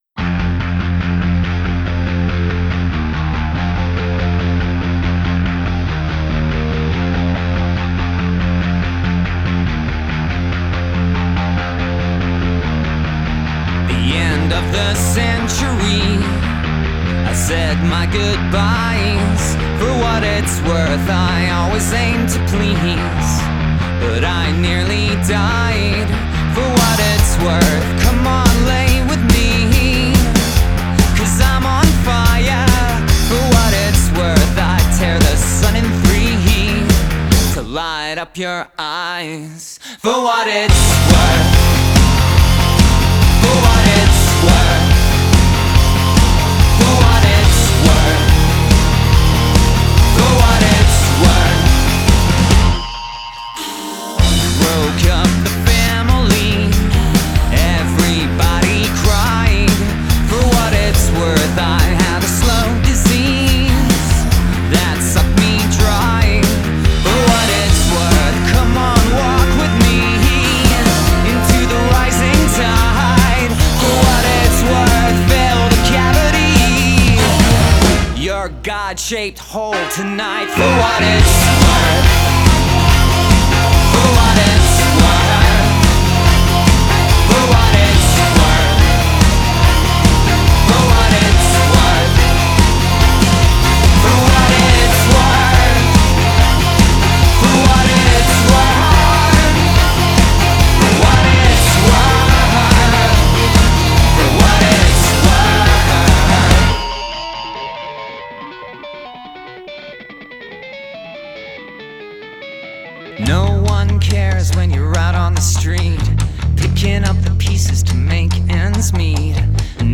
Alternative rock Indie rock Post punk